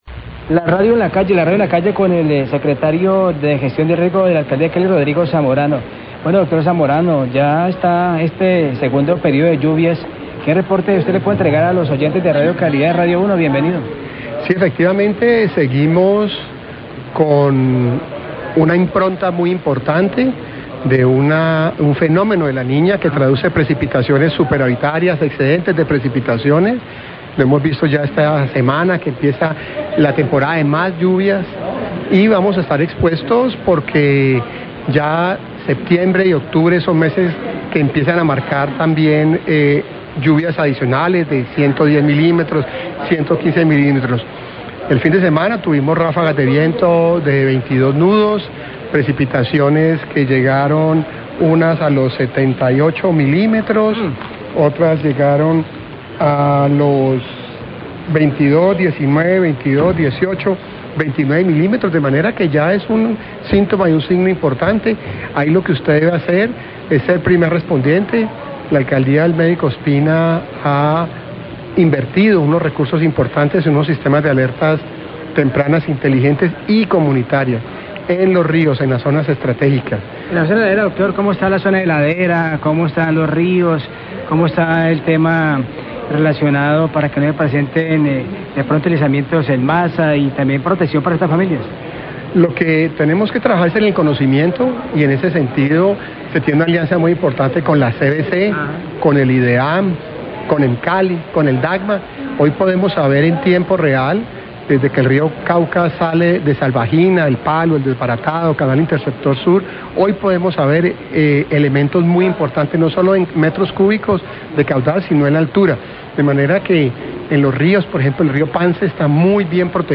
Rodrigo Zamorano da recomendaciones para la temporada de lluvias y monitoreo de ríos, Radio calidad 1245pm